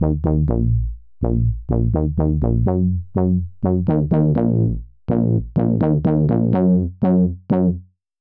Bigger Bass
Re-amping is a technique by which you run a synth or other instrument through a speaker and record the output with a microphone.
This works great on synth bass, such as this one from Roland Cloud SH-101
JC-120-Bass.wav